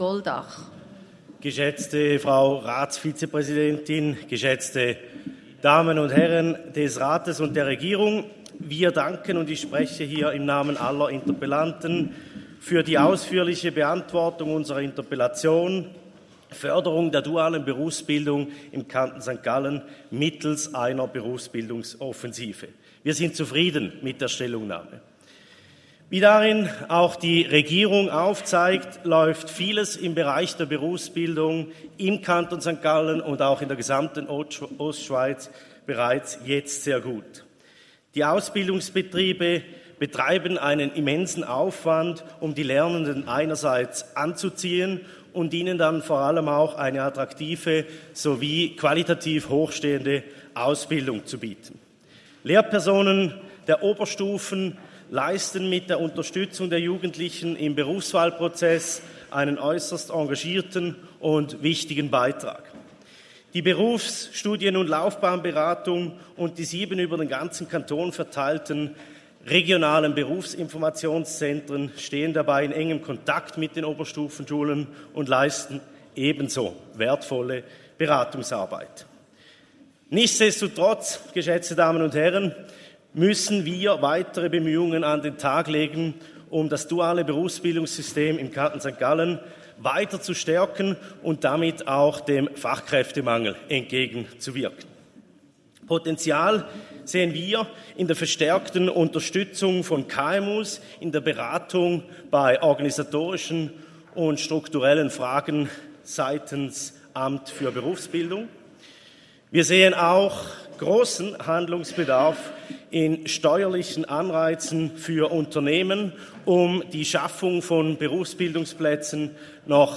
20.2.2024Wortmeldung
Session des Kantonsrates vom 19. bis 21. Februar 2024, Frühjahrssession